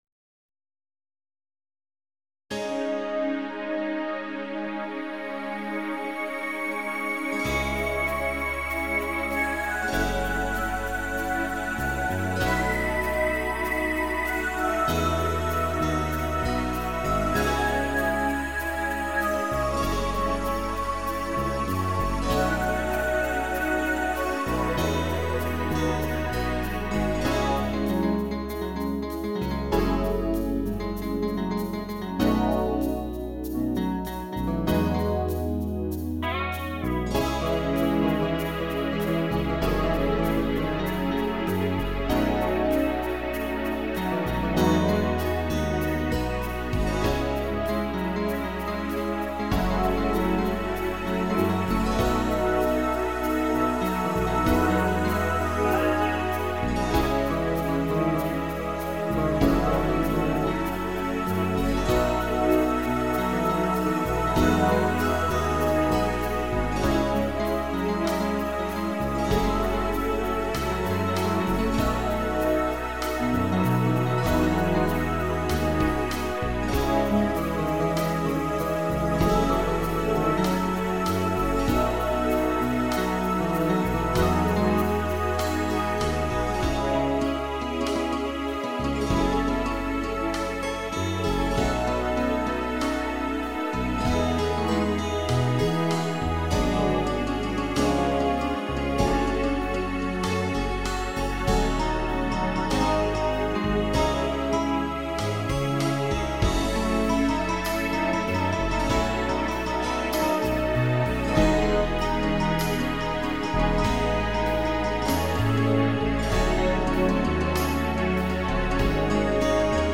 Sottofondo musicale